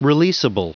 Prononciation du mot releasable en anglais (fichier audio)
Prononciation du mot : releasable